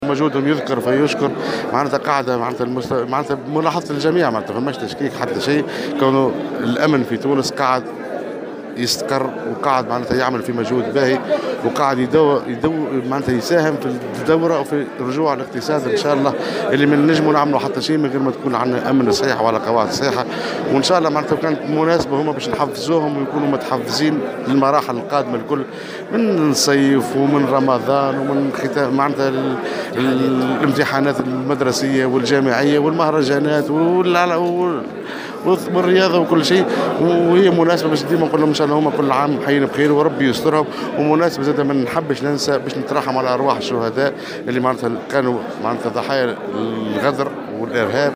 قال والي سوسة عادل الشليوي، خلال إشرافه اليوم الأربعاء 18 أفريل 2018 على موكب الاحتفال الجهوي بالذكرى 62 لعيد قوات الأمن الداخلي، إن الأمن في تونس في استقرار بفضل المجهودات الهامة التي تقوم بها الوحدات الأمنية.
وأضاف الوالي في تصريح لمراسل الجوهرة اف ام، أنه لا يمكن تحقيق نمو الاقتصاد وعودة الاستثمار دون توفير الأمن، متابعا أن هذه الاحتفالات مناسبة لتحفيز الوحدات الأمنية للمناسبات القادمة خلال الموسم الصيفي من رمضان وامتحانات مدرسية وجامعية ومهرجانات.